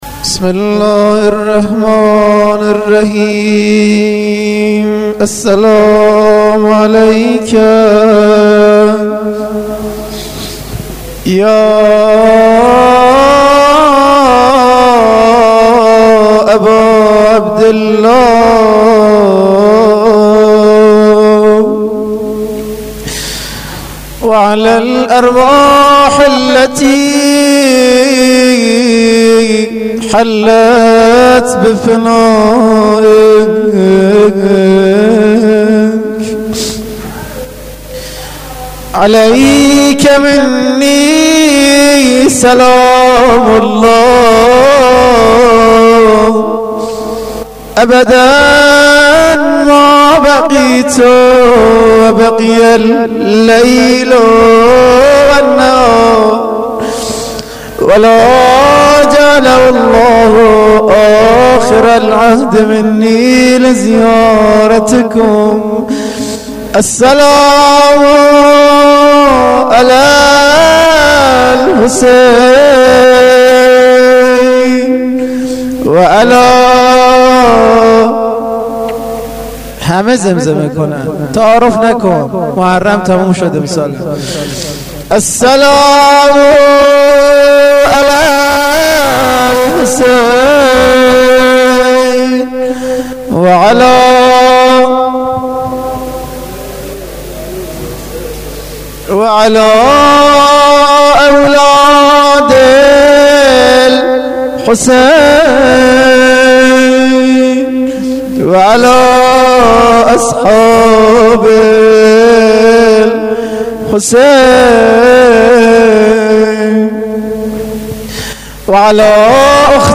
روضه شهادت قاسم